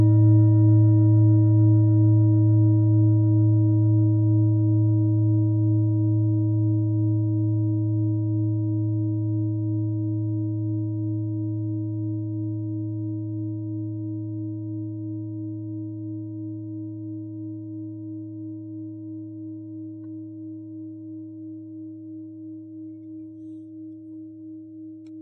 Nepal Klangschale Nr.57
Die Besonderheit dieser Klangschale liegt in ihrem starken Boden und ihrer relativ dazu dünnen Wandung. Dadurch bedarf es nur sanfter Schläge, um die Schale zum starken Schwingen anzuregen.
Hörprobe der Klangschale
Diese Frequenz kann bei 224Hz hörbar gemacht werden, das ist in unserer Tonleiter nahe beim "A".
klangschale-nepal-57.wav